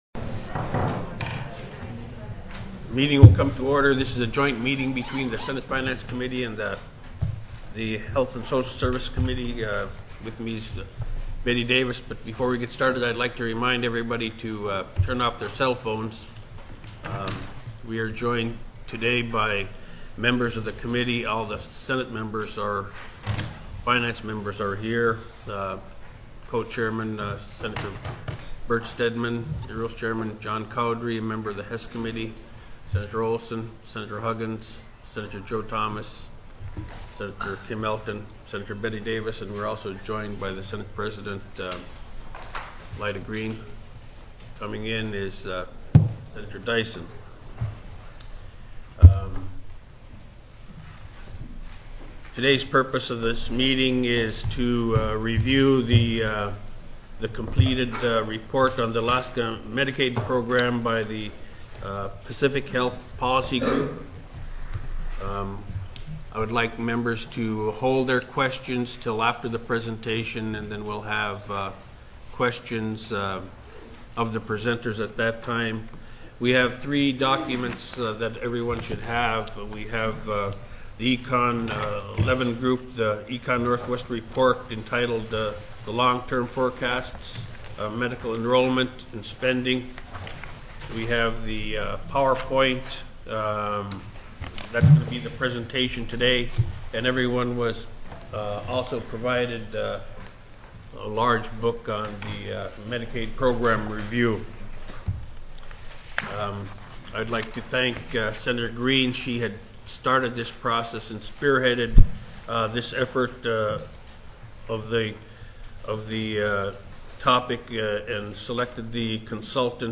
Alaska Medicaid Program Review Presentation